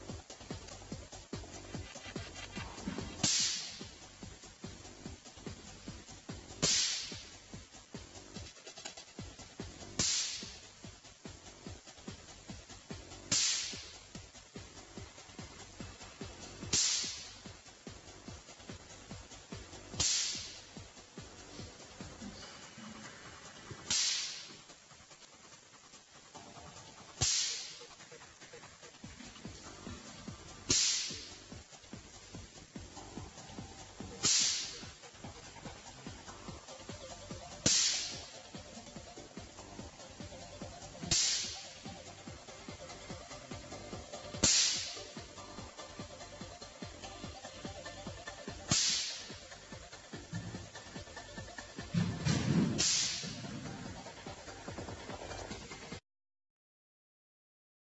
Cambridge Teslathon 2001